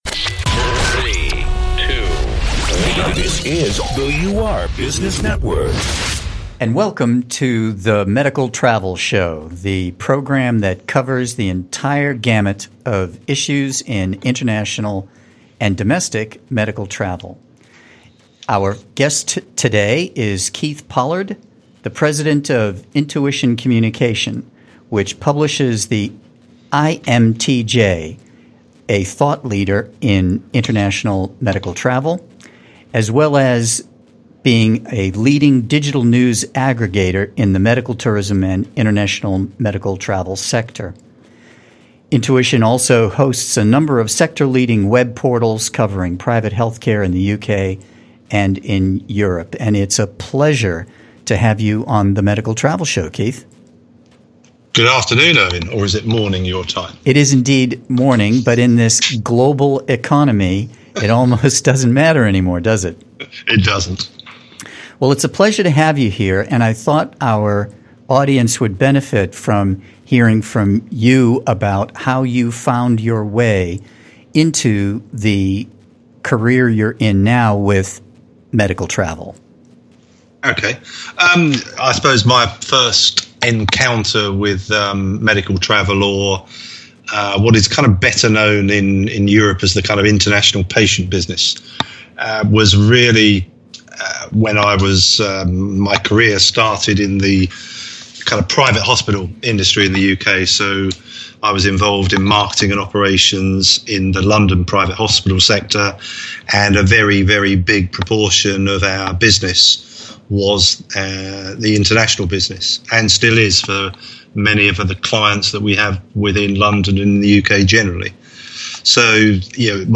The International Medical Travel Journal (IMTJ), hosted the second annual Medical Travel Summit at the Royal Garden Hotel in London on April 15-16, 2015.